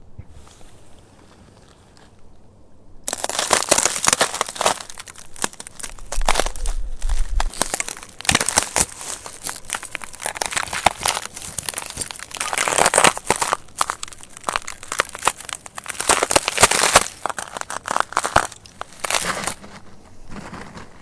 水花四溅
描述：在浴缸中泼水。使用Zoom H4N进行改装。
Tag: 水平涡流 飞溅 沐浴 飞溅 液体 现场记录